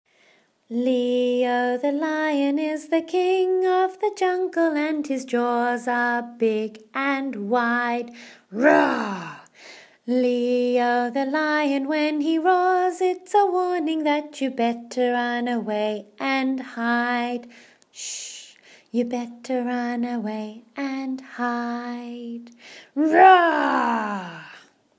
Sh Sound - Baby